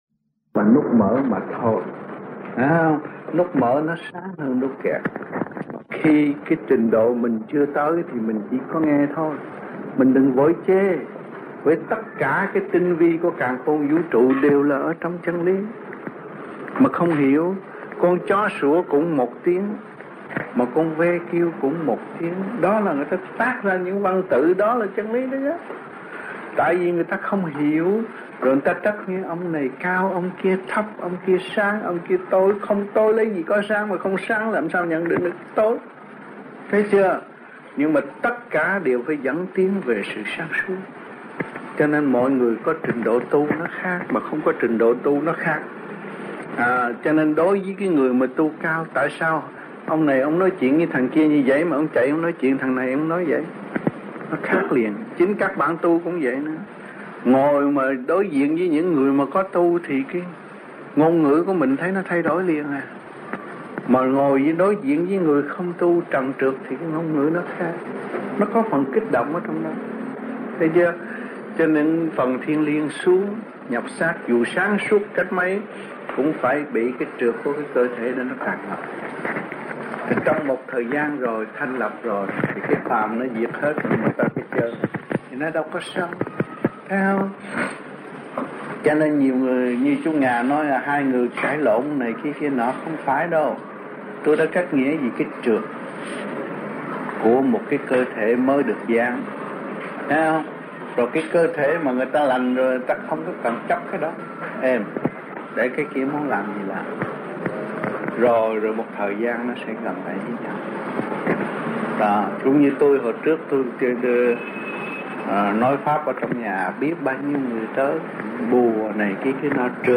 1978 Đàm Đạo